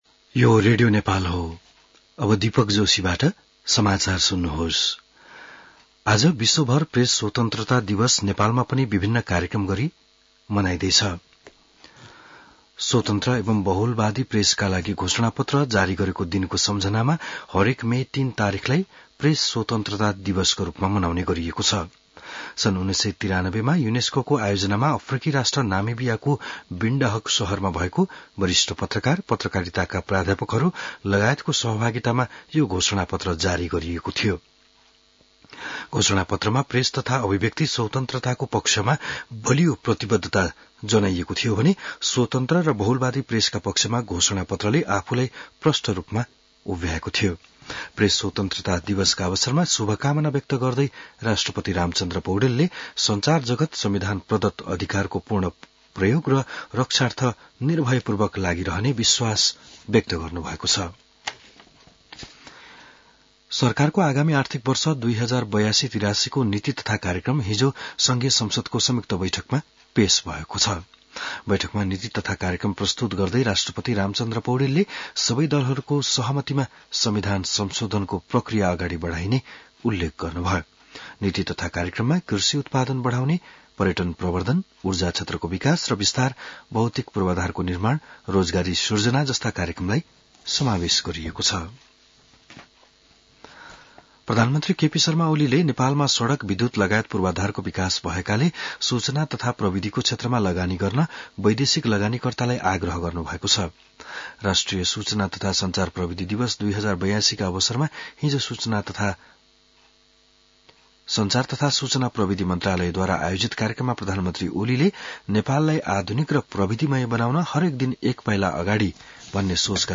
An online outlet of Nepal's national radio broadcaster
बिहान १० बजेको नेपाली समाचार : २० वैशाख , २०८२